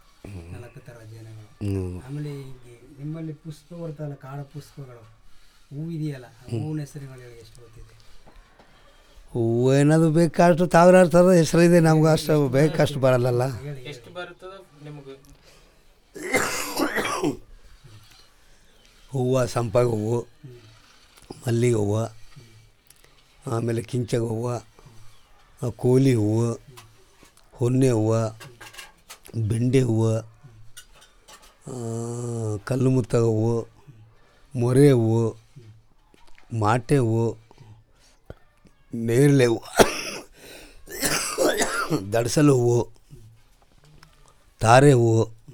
Elicitation of words about flowers and related